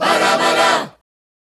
Ba Da, Ba Da 152-E.wav